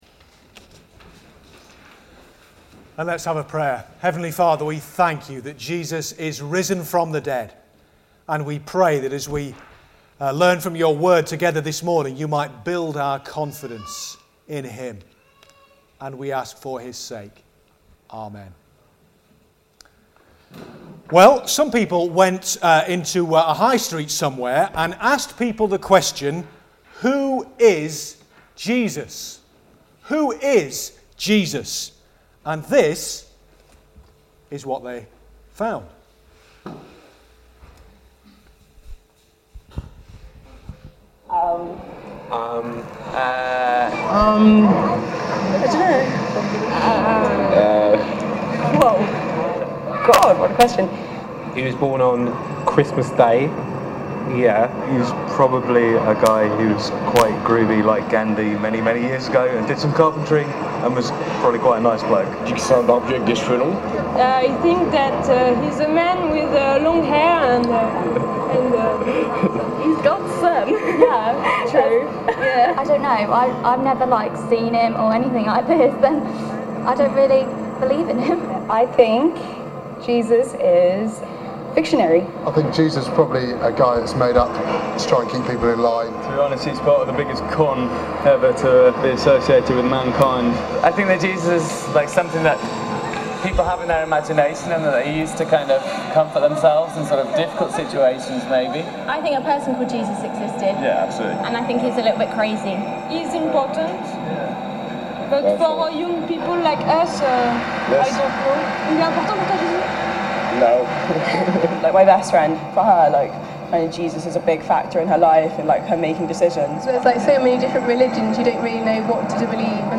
Continuing our sermon series ‘Christ is risen! The meaning and significance of Jesus’ Resurrection’ ‘The Defeat of Doubt’ – John 20:24-31